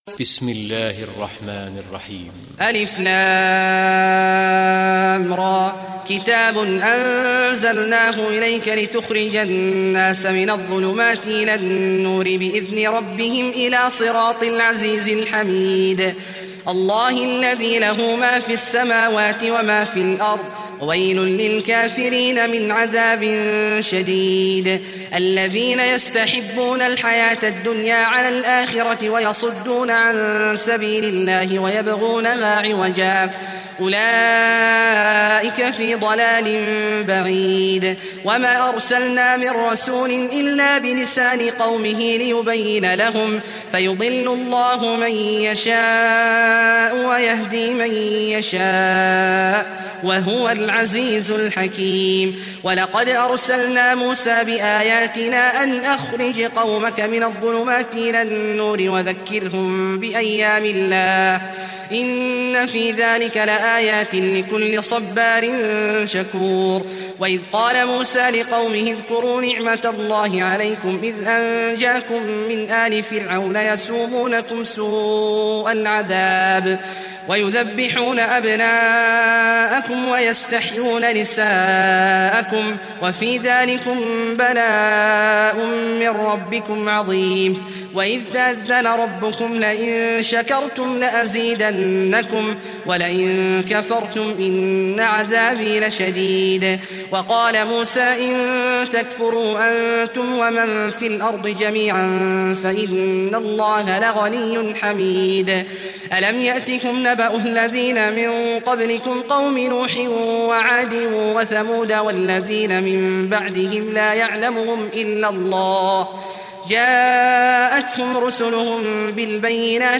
Surah Ibrahim Recitation by Sheikh Abdullah Juhany
Surah Ibrahim, listen online mp3 tilawat / recitation in Arabic in the beautiful voice of Sheikh Abdullah Awad al Juhany.
14-surah-ibrahim.mp3